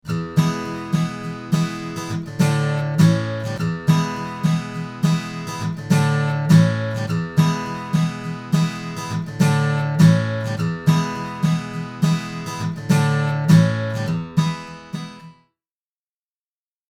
Below are a series of examples of an acoustic guitar with various types of reverb added. The acoustic guitar was recorded in a dry room and repeats the same phrase in each example. It’s heard first without the reverb effect, and then  the reverb is added.
Small Room – Acoustic Guitar
The guitar with the Small Room reverb at a moderately low volume is hardly noticeable, while the Large Hall is fairly obvious.
Guitar_Small_Room.mp3